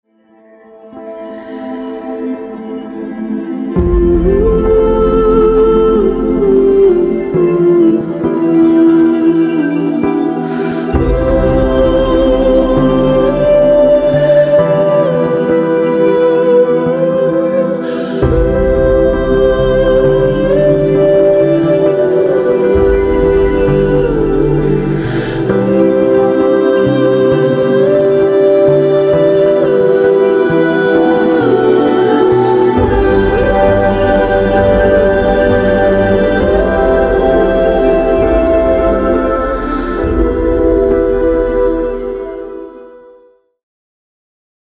Ethereal